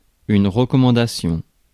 Ääntäminen
France (Paris): IPA: [ʁə.kɔ.mɑ̃.da.sjɔ̃]